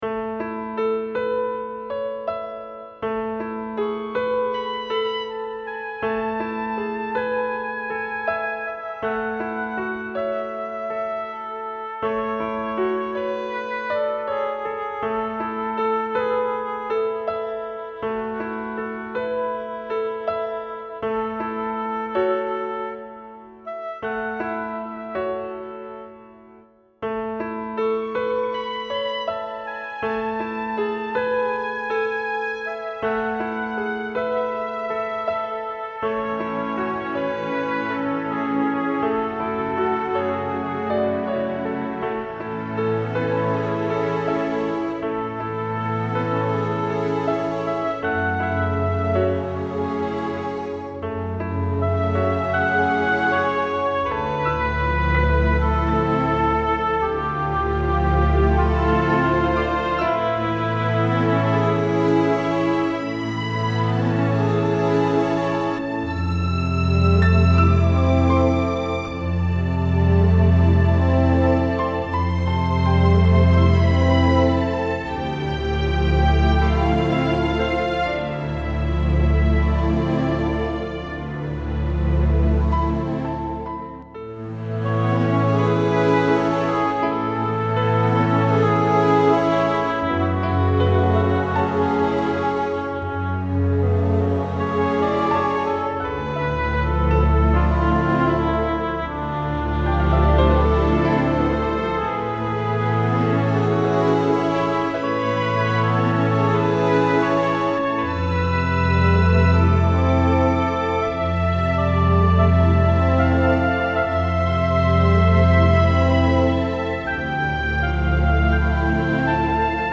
Cine romántico
piano
banda sonora
melodía
romántico
sintonía